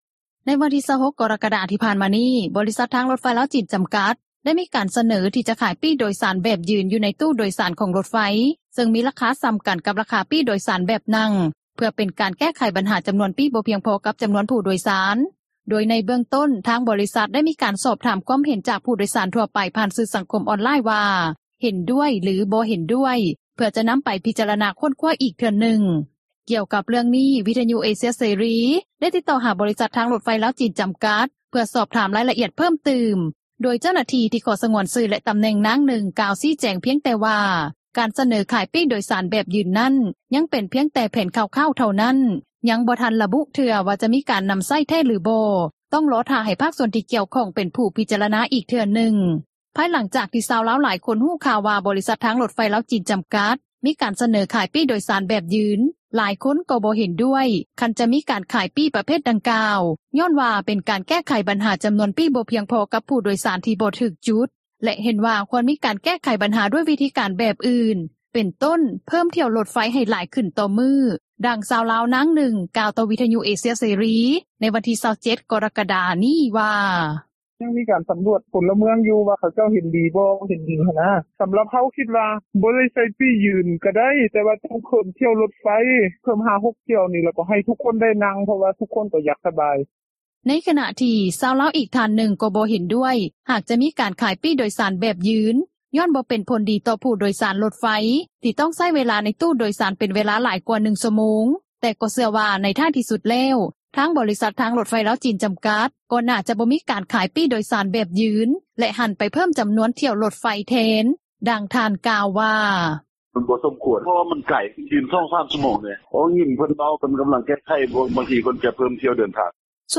ດັ່ງຊາວລາວ ນາງນຶ່ງ ກ່າວຕໍ່ວິທຍຸເອເຊັຽເສຣີ ໃນມື້ວັນທີ່ 27 ກໍຣະກະດາ ນີ້ວ່າ:
ດັ່ງຜູ້ໂດຍສານ ນາງນຶ່ງ ກ່າວວ່າ: